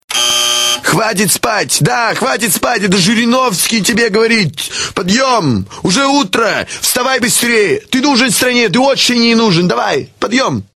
Главная » Рингтоны » Рингтоны на будильник